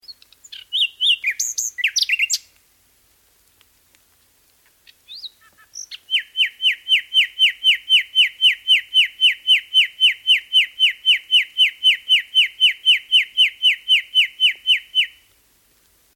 Birdsong 4